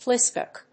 flý・spèck